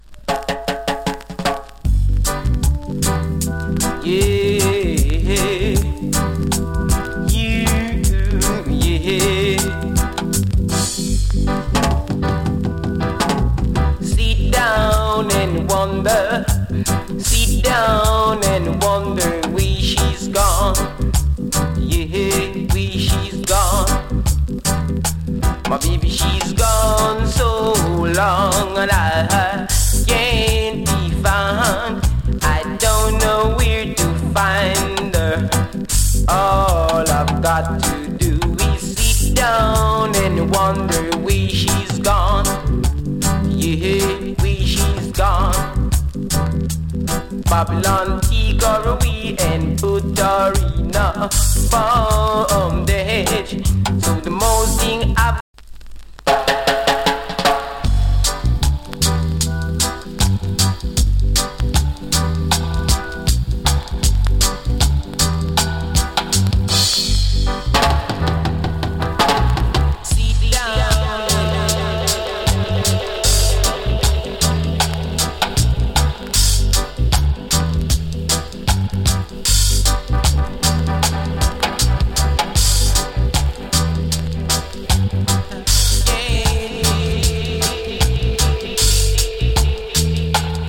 チリ、パチノイズ少し有り。
75年 NICE ROOTS ROCK !! & FINE DUB.